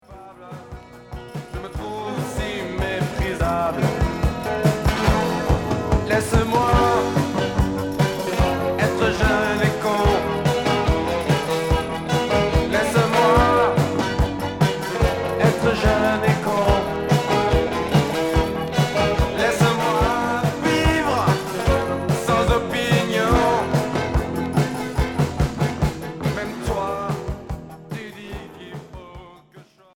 Rock new wave